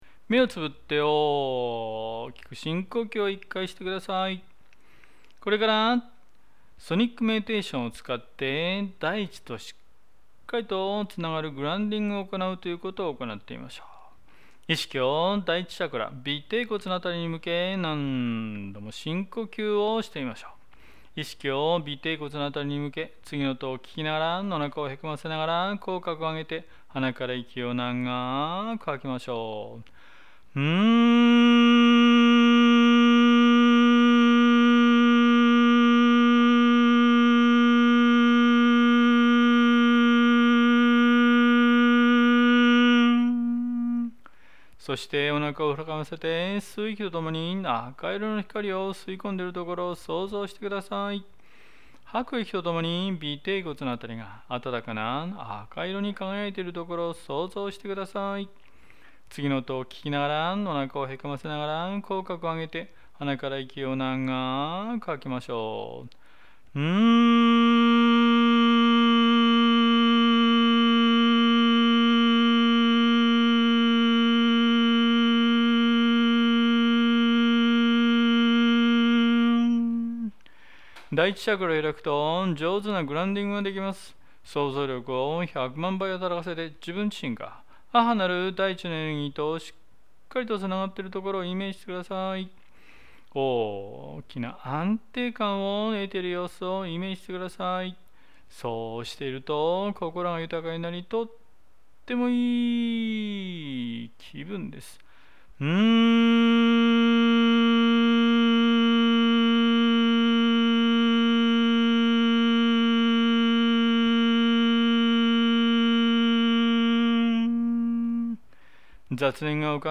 瞑想を誘導する「先生」が、音と光と意識と言葉のエネルギーを使って、 「生徒」を瞑想の意識状態に効率的に誘導します。
そこで、誘導瞑想は、瞑想ティーチャーからのナレーションを聞きながら瞑想するので、 それを聞いてイメージをしていけば、雑念が浮かびにくく、すぐに深い瞑想状態に容易に入れます。